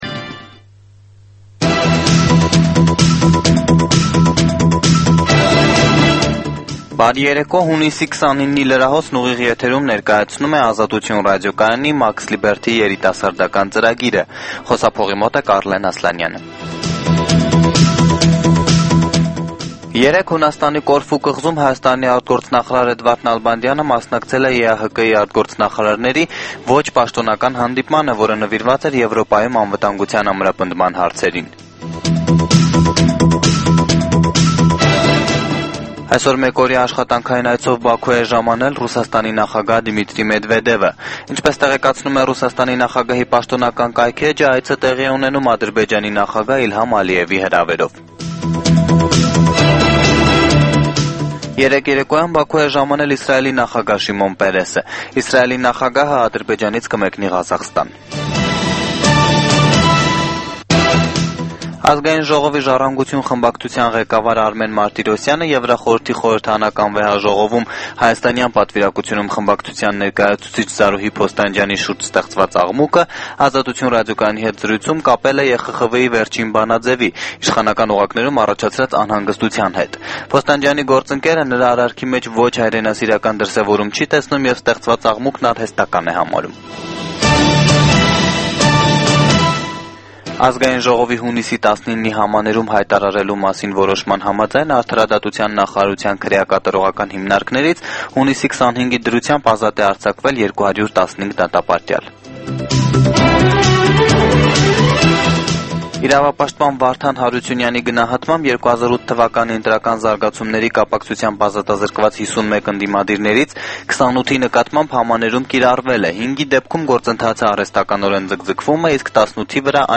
Լուրեր
Տեղական եւ միջազգային վերջին լուրերը ուղիղ եթերում: